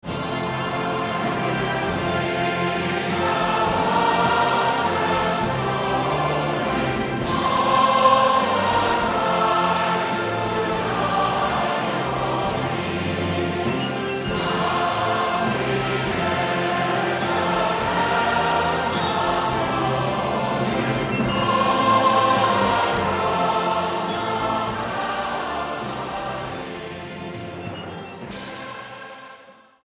All Souls Orchestra - I Will Sing The Wondrous Story: The Best Of Prom Praise Hymns & Songs
STYLE: Hymnody